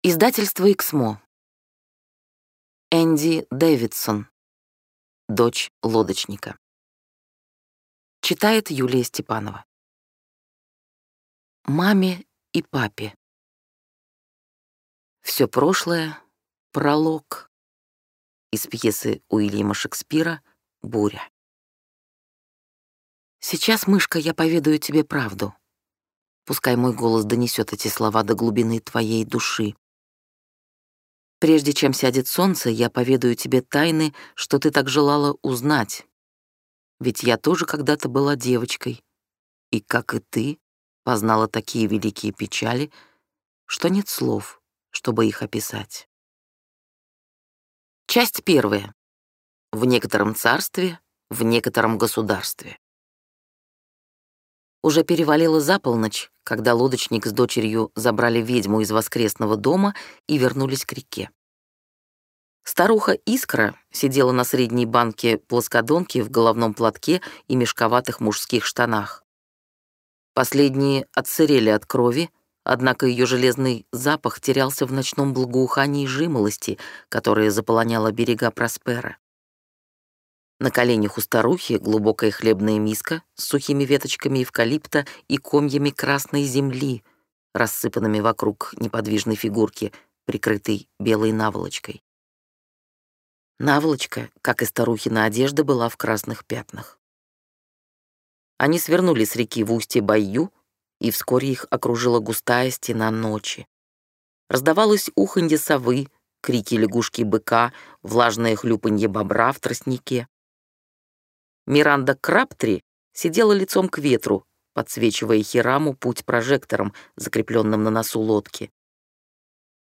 Аудиокнига Дочь лодочника | Библиотека аудиокниг